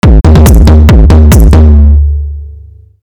мой первый бит зацените :gg: